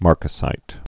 (märkə-sīt, -zīt)